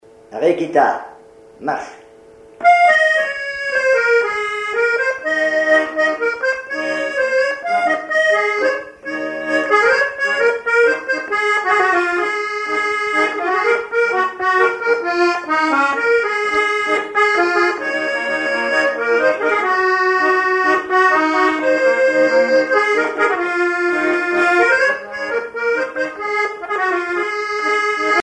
accordéon(s), accordéoniste
danse : marche
Pièce musicale inédite